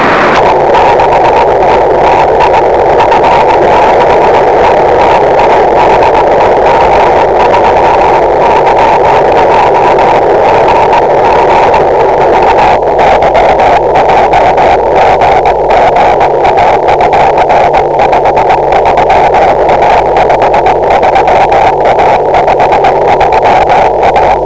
• AURORES BORÉALES
télégraphie morse est ici encore le moyen le plus efficace.